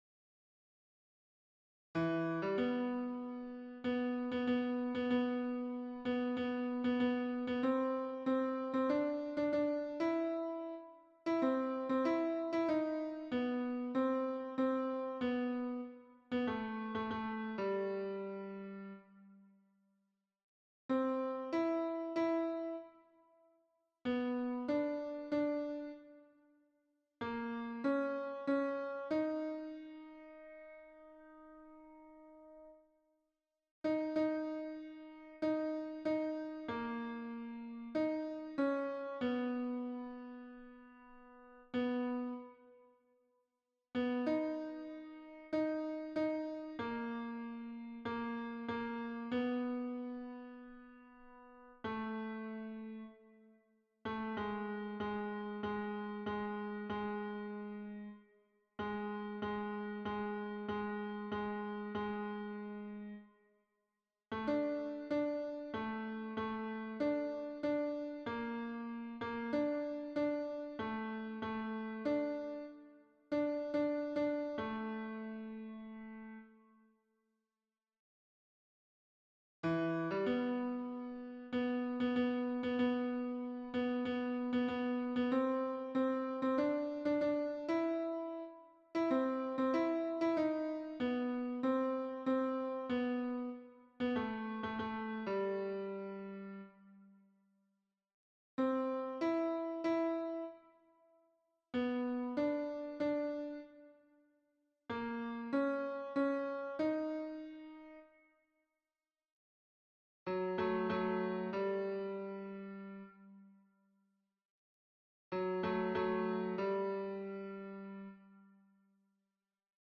MP3 version piano
Tenor